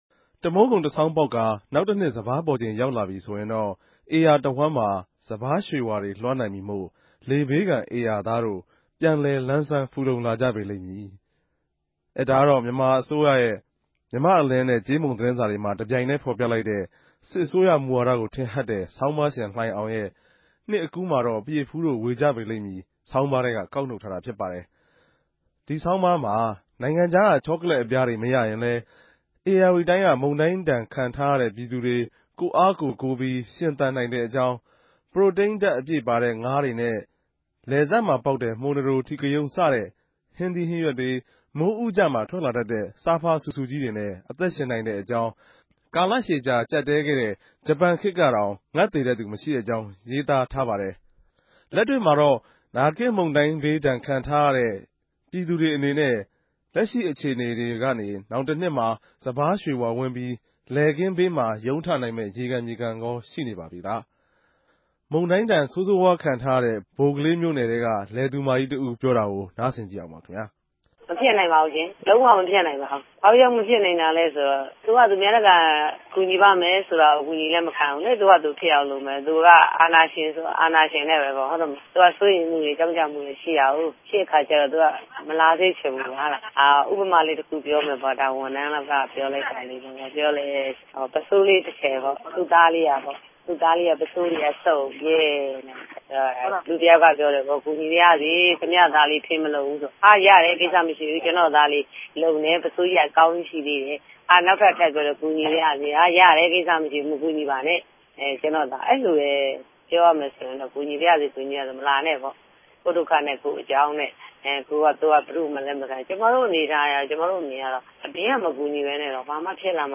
သုံးသပ်တင်ူပခဵက်။